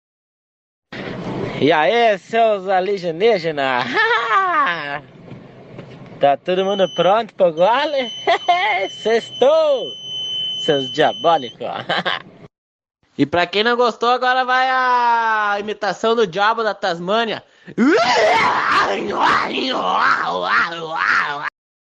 Eae seus aligenigena/Imitação diabo da tasmânia